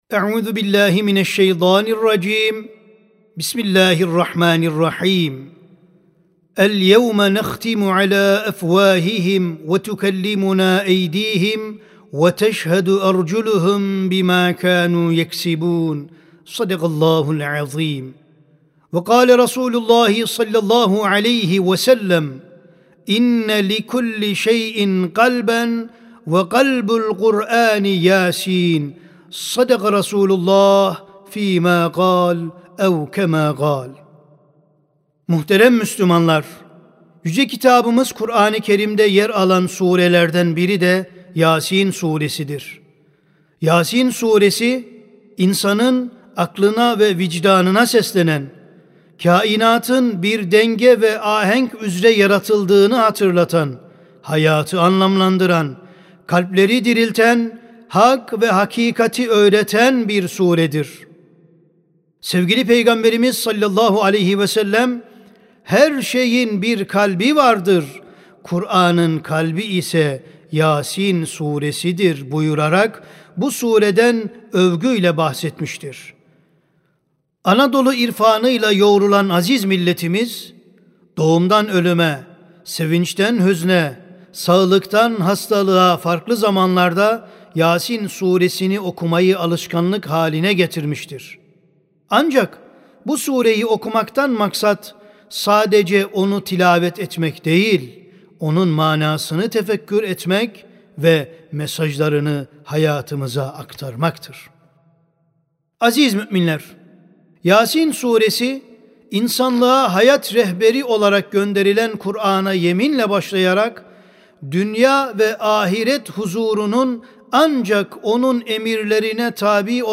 HUTBE
Sesli Hutbe (Yâsîn Sûresi, Kur’an’ın Kalbi).mp3